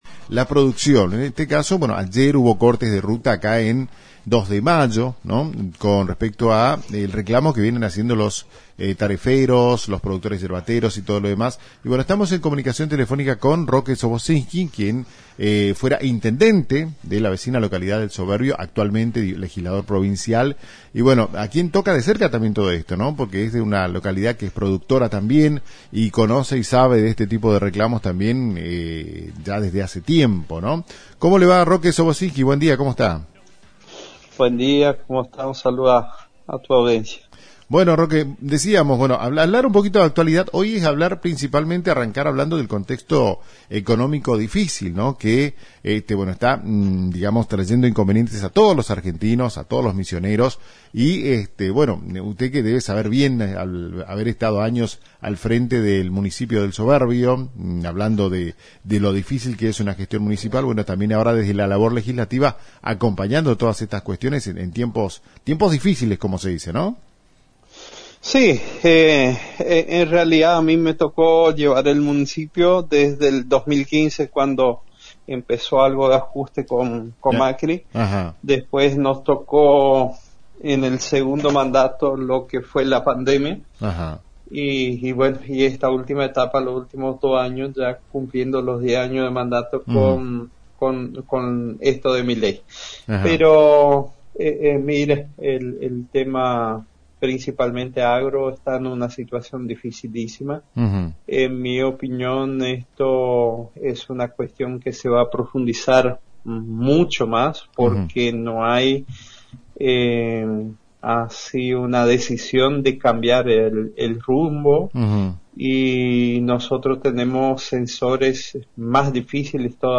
En diálogo con “Agenda 360” en FM La Top y FM Los Lapachos, el legislador provincial abordó temas referidos a la situación económica del país y el reclamo yerbatero.